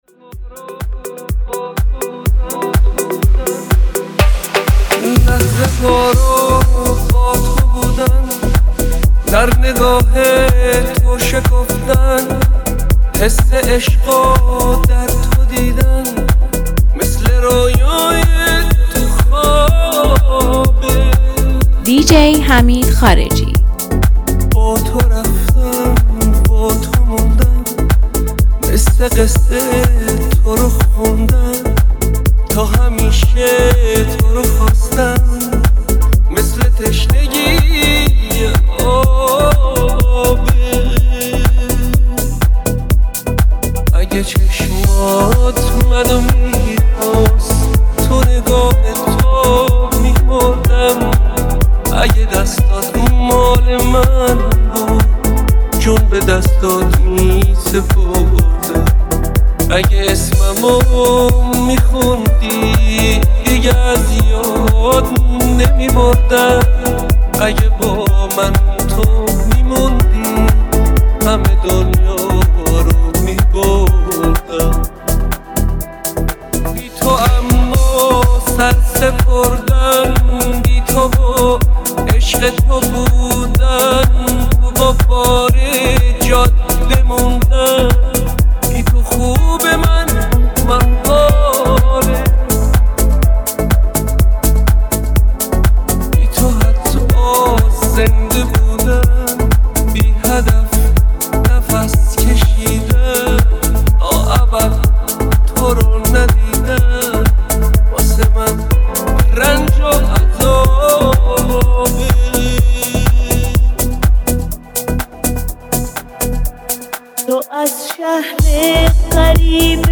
یه میکس ترکیبی جدید و فوق‌العاده براتون آماده کردیم!